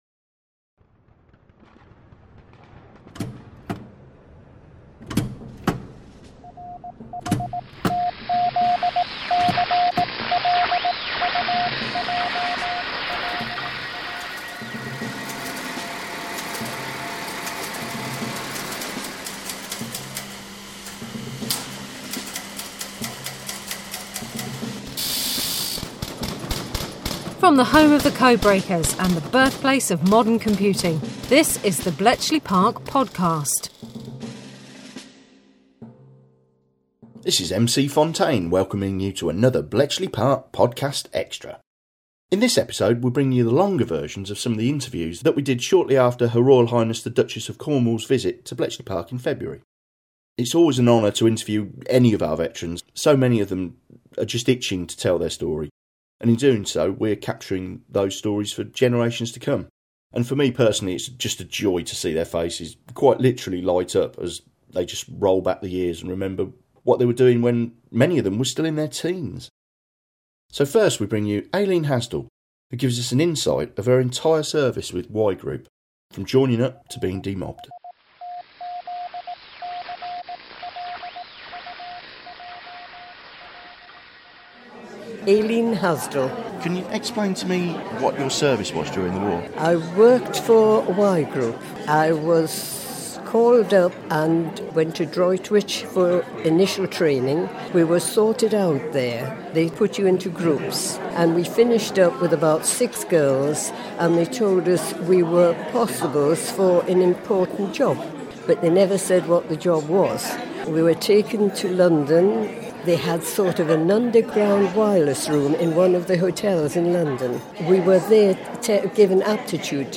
In this episode we bring you the longer versions of some of the interviews featured in the last episode of The Bletchley Park Podcast.